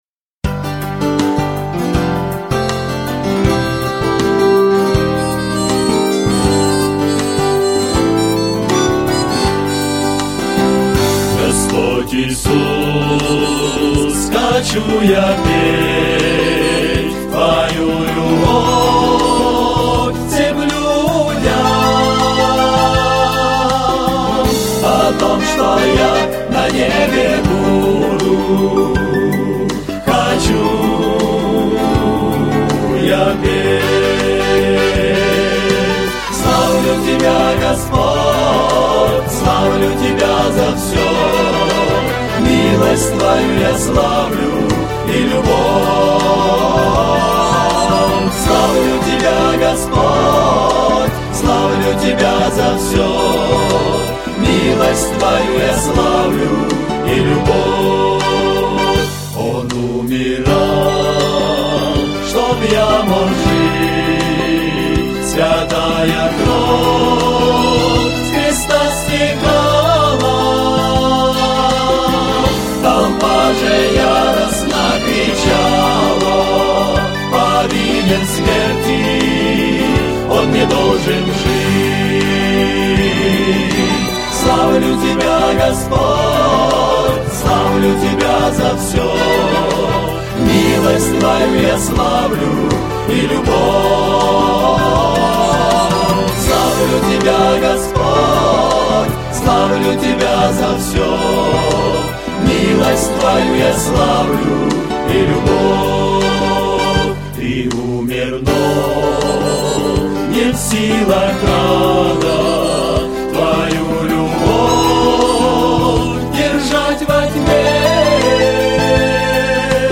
499 просмотров 833 прослушивания 73 скачивания BPM: 80